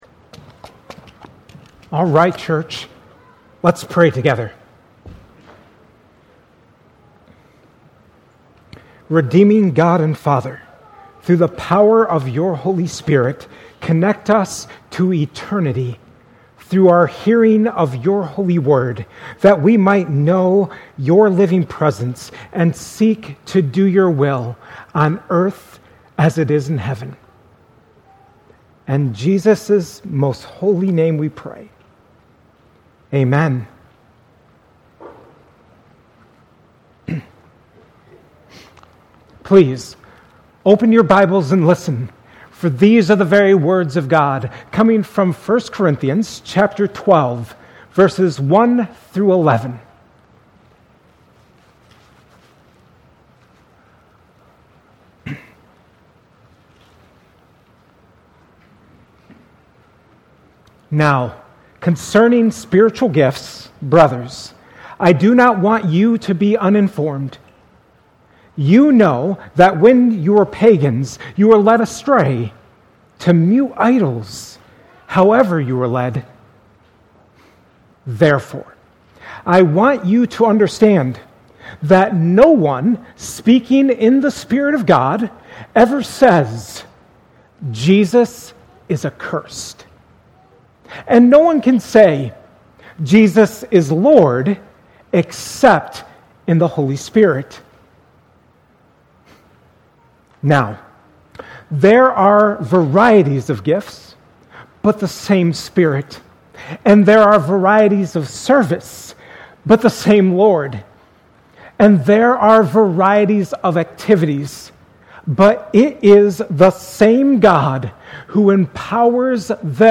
2025 at Cornerstone Church in Pella.